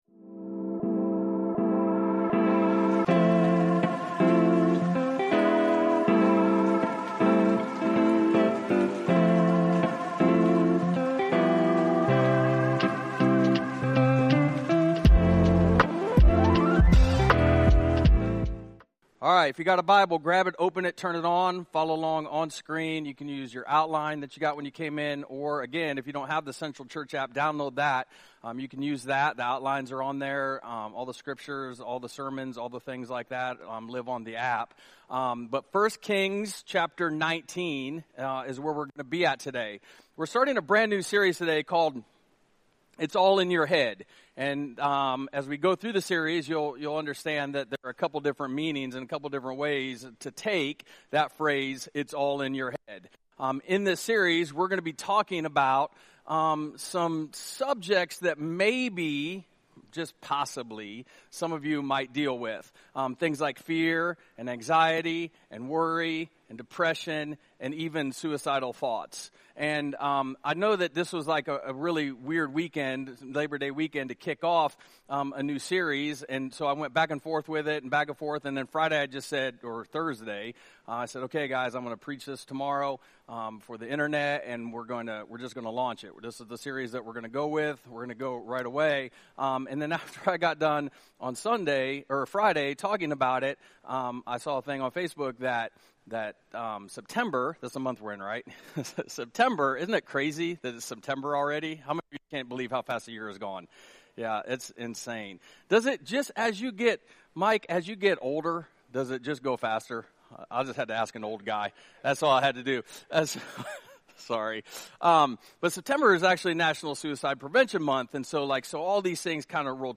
We kicked off our new series - It's All In Your Head - with the message, "Who's Voice Is It?" - inspired by 1 Kings 19:1-18.